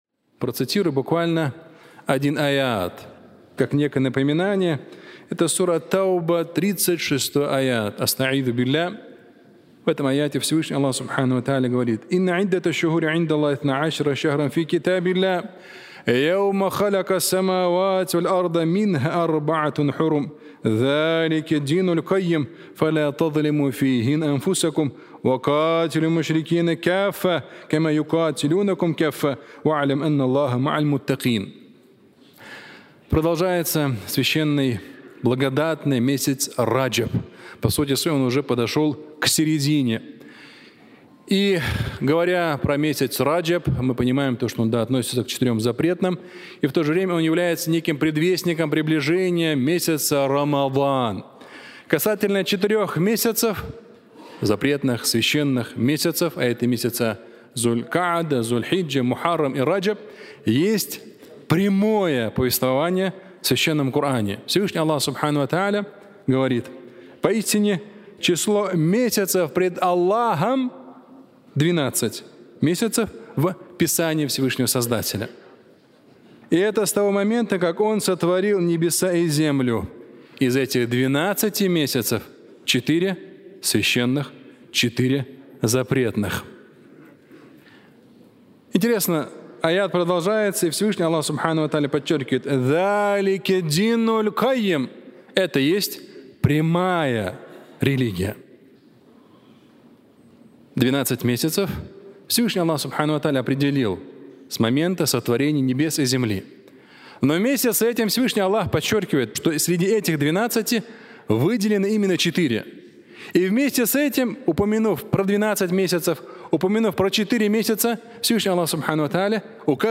Не притесняй себя (аудиолекция)
Пятничная проповедь